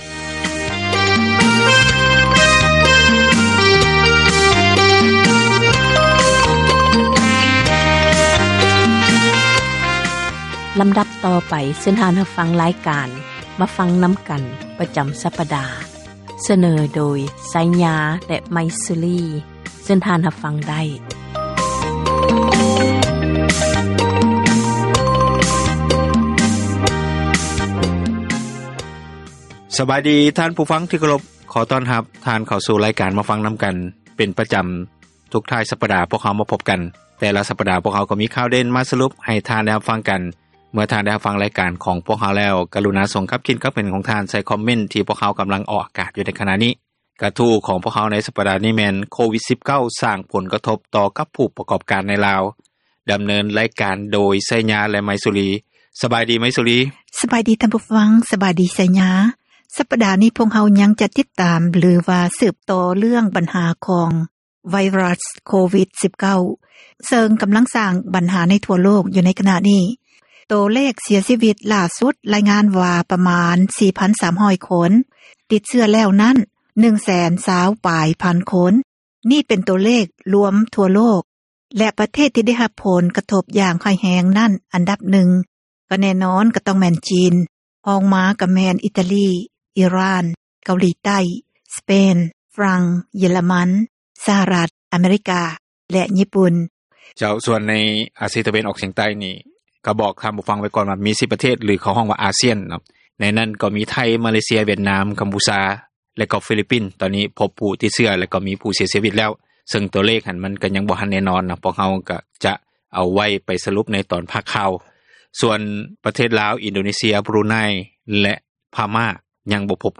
"ມາຟັງນຳກັນ" ແມ່ນຣາຍການສົນທະນາ ບັນຫາສັງຄົມ ທີ່ຕ້ອງການ ພາກສ່ວນກ່ຽວຂ້ອງ ເອົາໃຈໃສ່ແກ້ໄຂ,